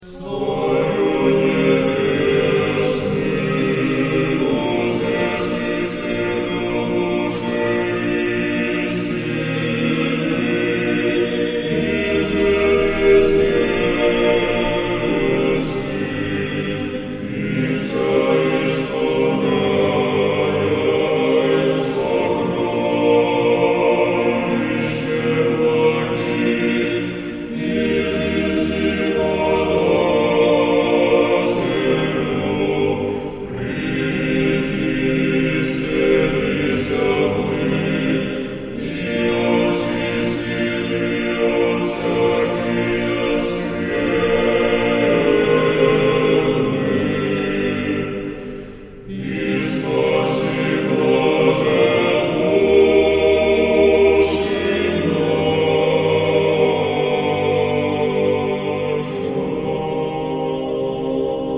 Аудиокнига Акафист иконе Богородицы «Призри на смирение» | Библиотека аудиокниг